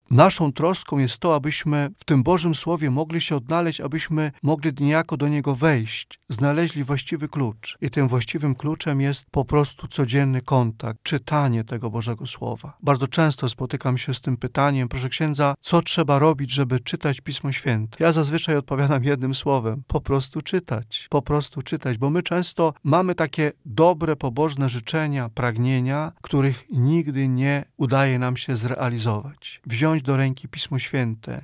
Nie potrzeba wielkich planów ani skomplikowanych metod, by rozpocząć regularnie czytanie Słowa Bożego – dodaje kapłan: